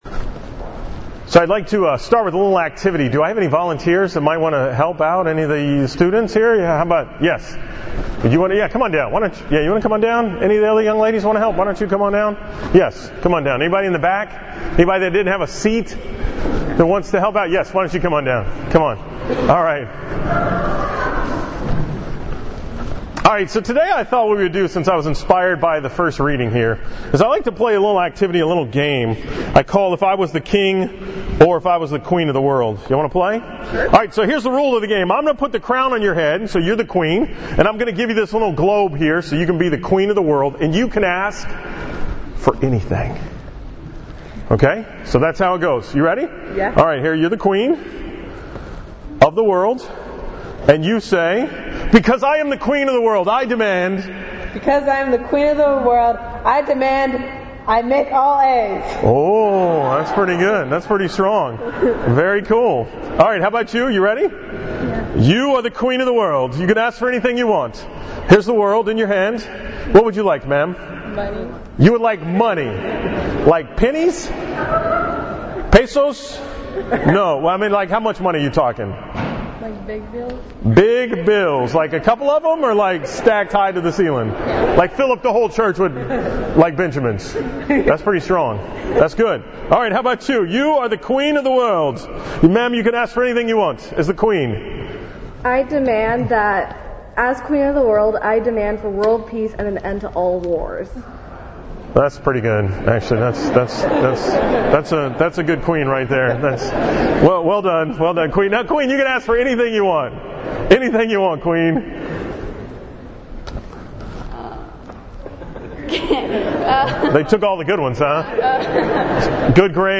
From the All School Mass at IWA on January 12, 2018